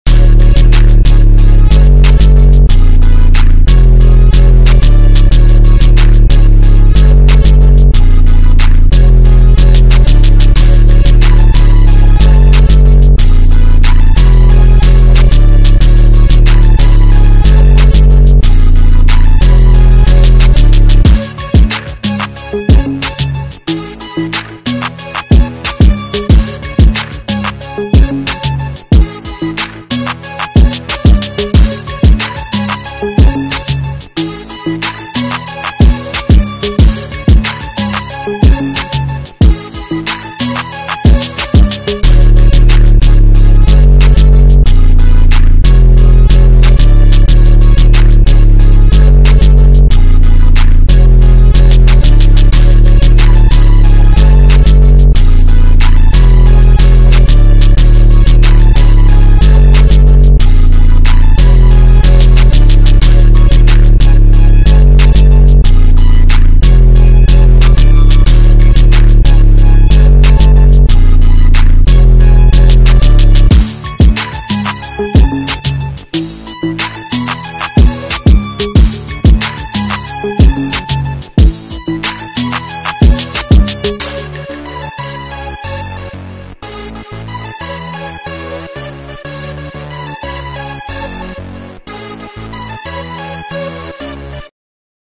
BPM - 183
KEY - F# min